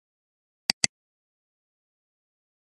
Click_-_Sound_Effect_HD